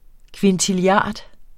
Udtale [ kventiliˈɑˀd ]